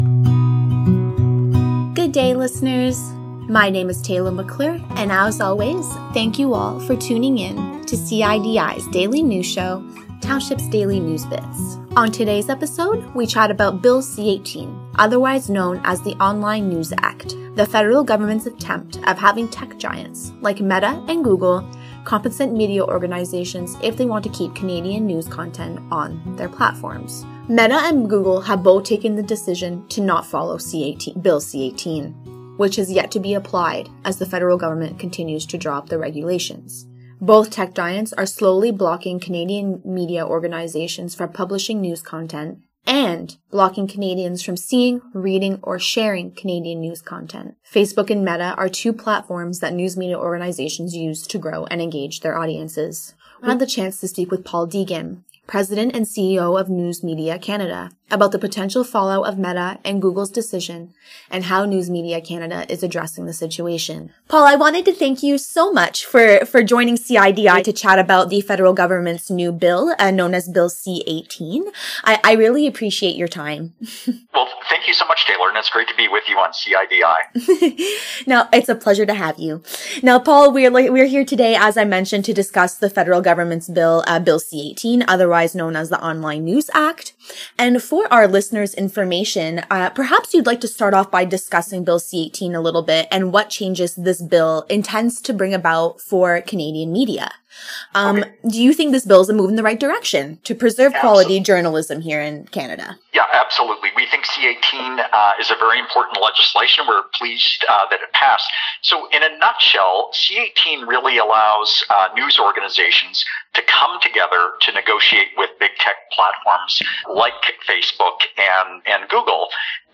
A conversation with Canadian Heritage Minister about Bill C-18
She is also the MP for the riding of Brome-Missisquoi in Quebec's Eastern Townships, which is also the home to CIDI, the Township's community radio station. The station had the opportunity to ask the Minister directly.